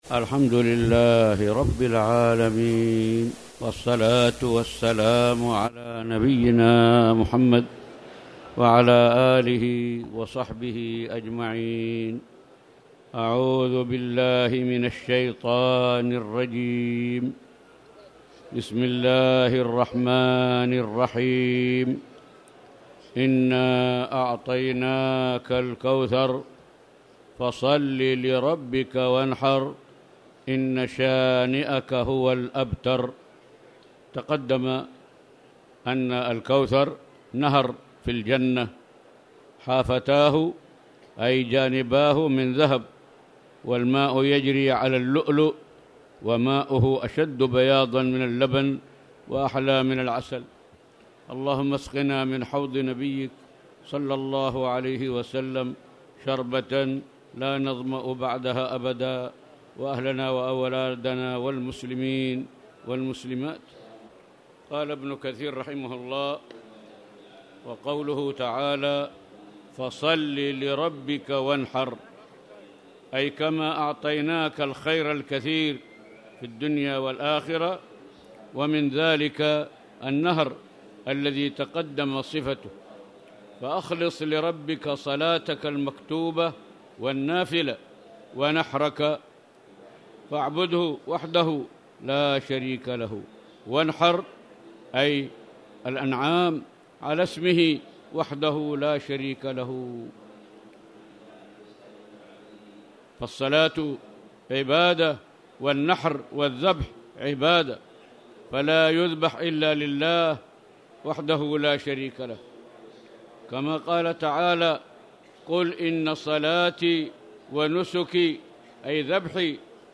تاريخ النشر ٢٧ رمضان ١٤٣٧ هـ المكان: المسجد الحرام الشيخ